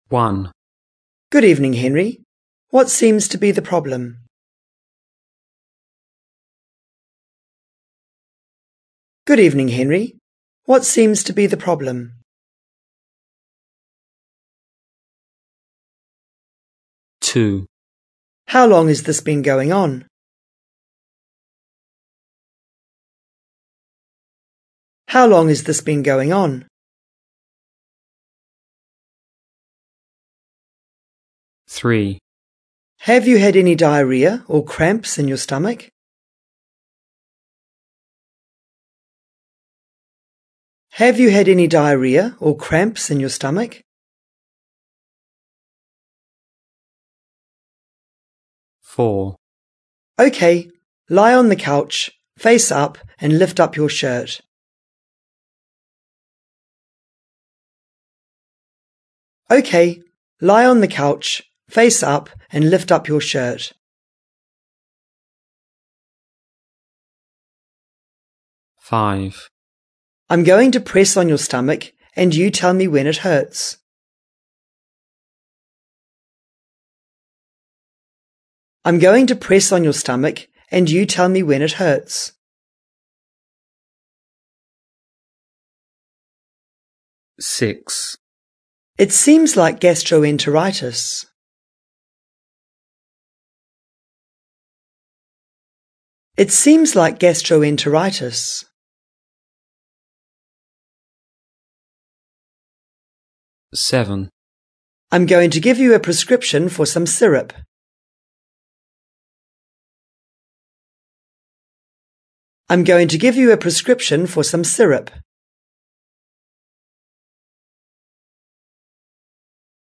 4. Dictation.
Listen again to the doctor.
You will hear each phrase twice.